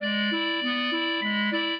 clarinet
minuet4-9.wav